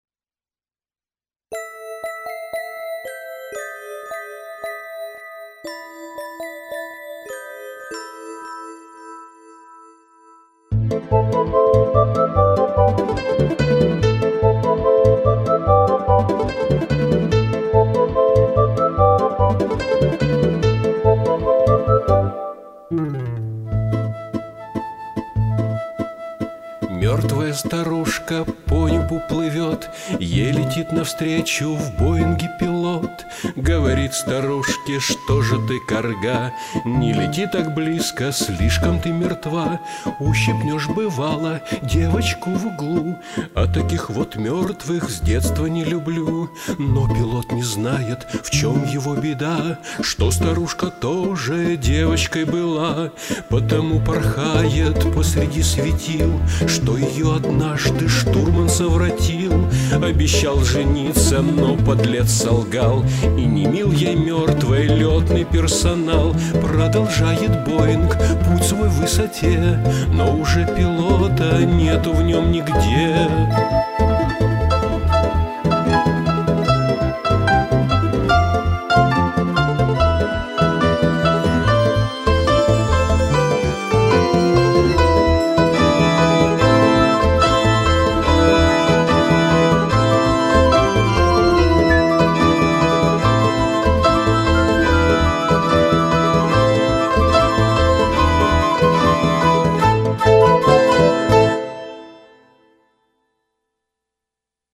Вокал перепишу позже.